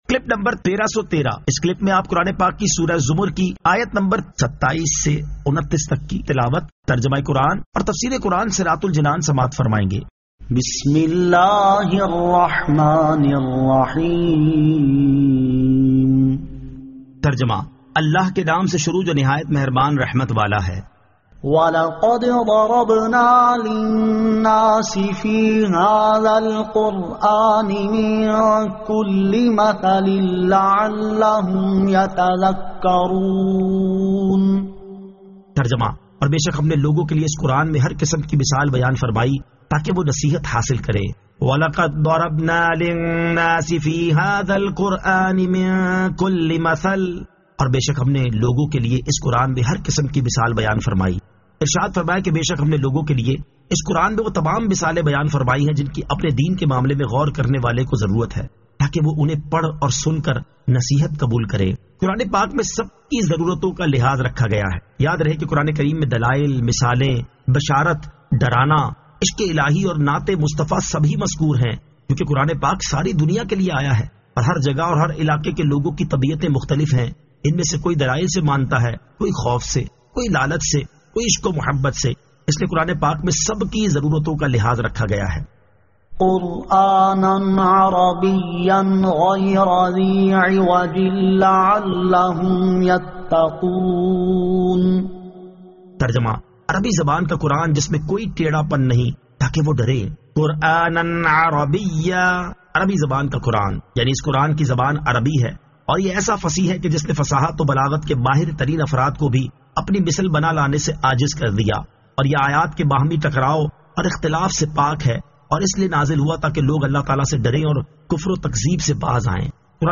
Surah Az-Zamar 27 To 29 Tilawat , Tarjama , Tafseer